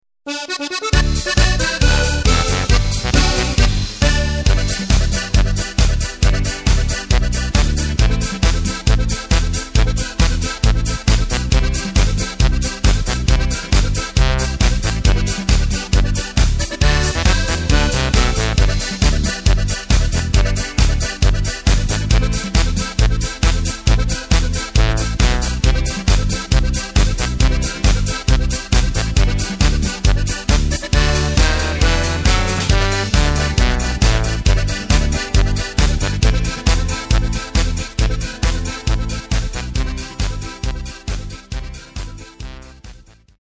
Tempo:         136.00
Tonart:            G
Playback mp3 Mit Drums